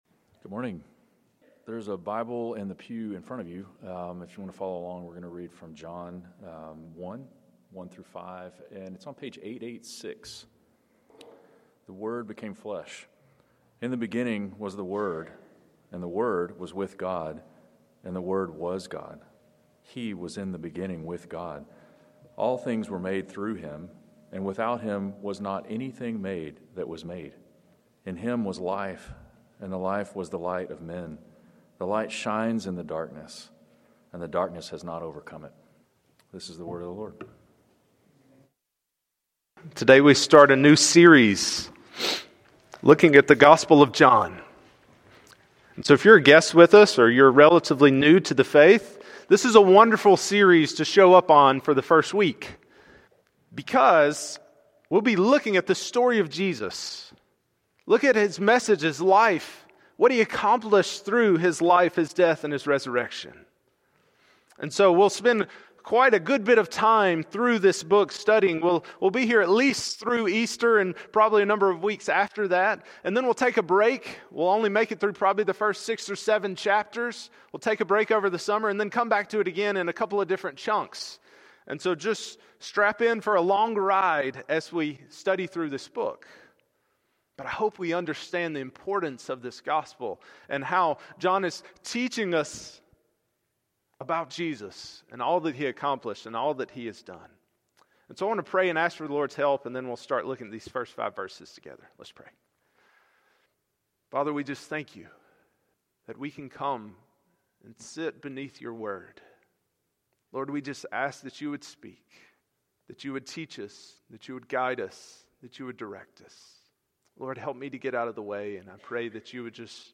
Passage: John 1:1-5 Sermon